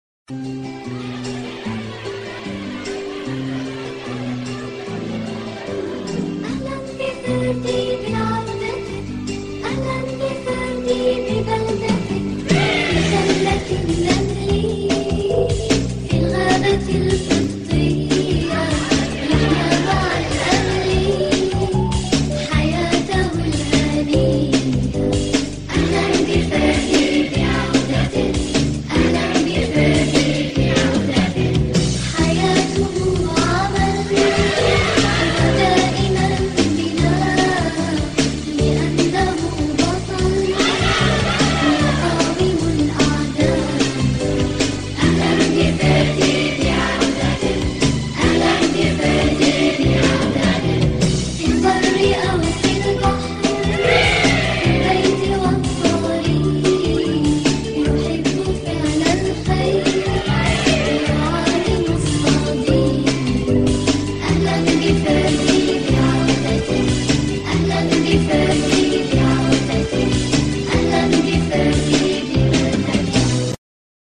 فيردي - الحلقة 1 مدبلجة